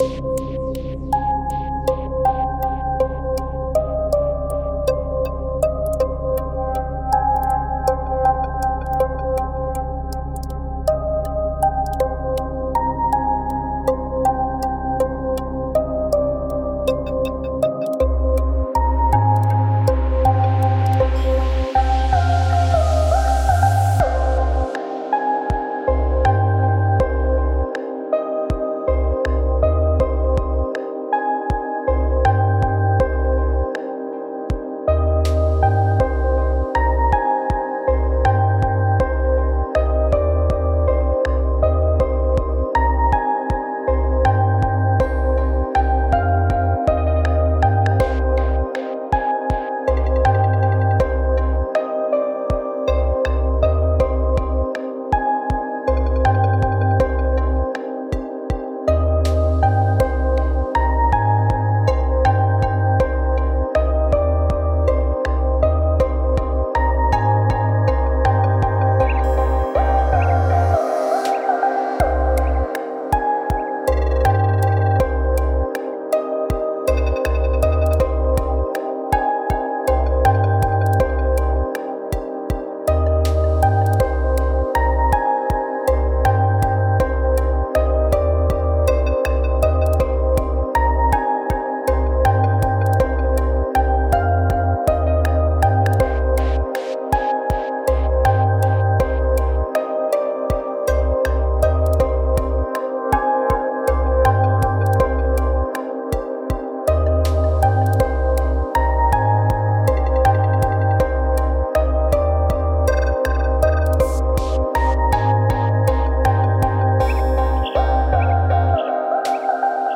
Аранж/сведение, оценка/критика (чилл)